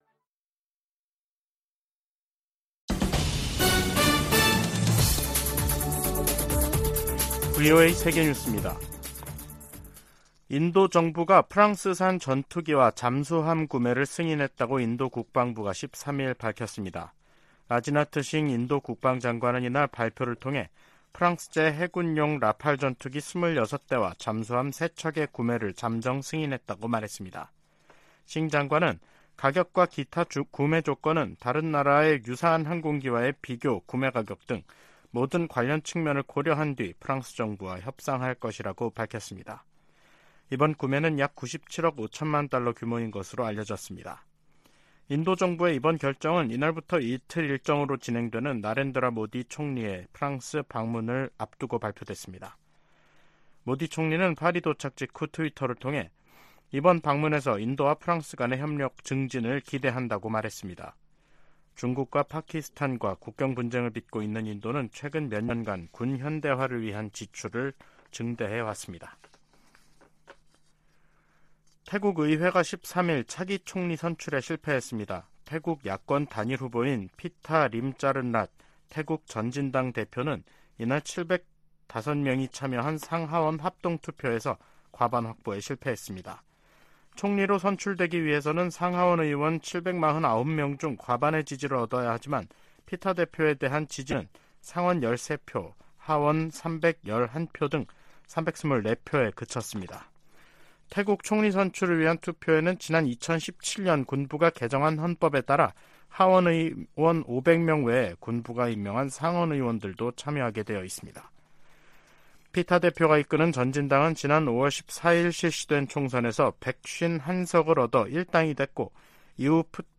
VOA 한국어 간판 뉴스 프로그램 '뉴스 투데이', 2023년 7월 13일 3부 방송입니다. 13일 북한 당국이 전날(12일) 발사한 탄도미사일이 고체연료 대륙간탄도미사일(ICBM) 화성-18호라고 밝혔습니다. 미국 정부와 의회, 유엔과 나토 사무총장, 한일 정상, 미한일 합참의장이 북한 정권의 장거리탄도미사일 발사를 강력히 규탄하며 대화에 나설 것을 촉구했습니다. 북한 주민들에게 자유와 진실의 목소리를 전해야 한다고 미국 의원들이 강조했습니다.